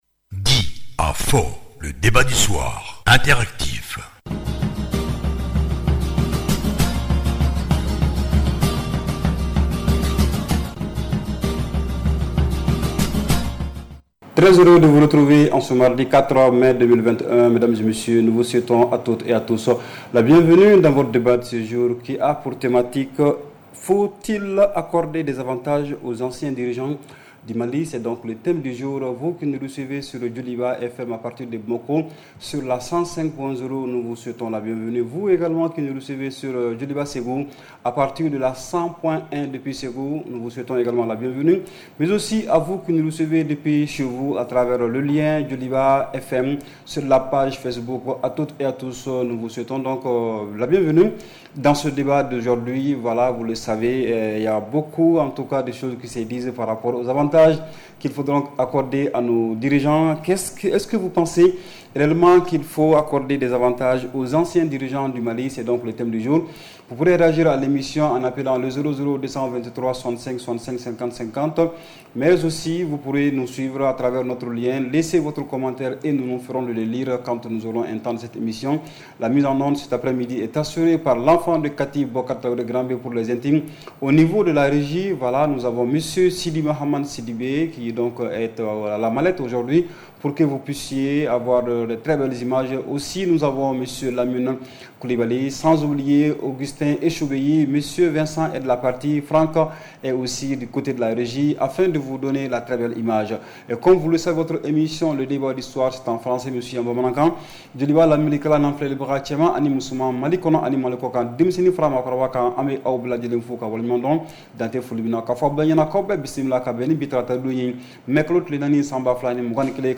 REPLAY 04/05 – « DIS ! » Le Débat Interactif du Soir